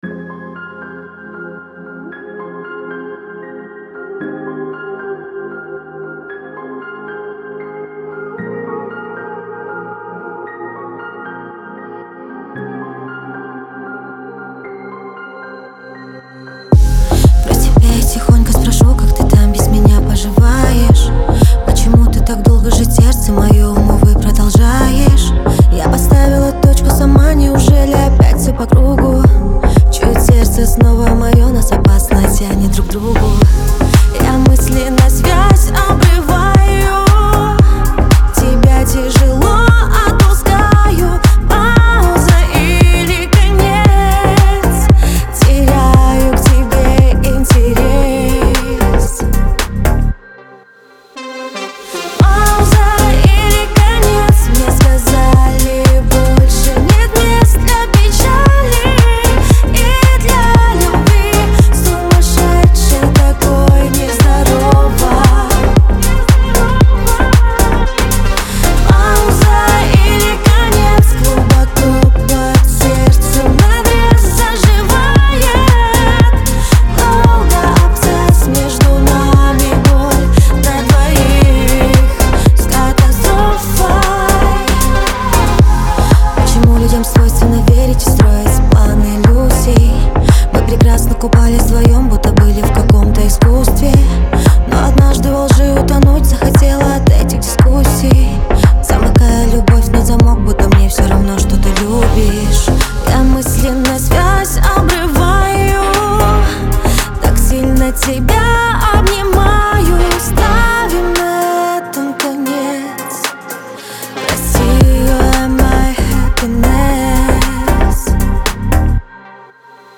Зажигательная музыка этого сезона
pop , грусть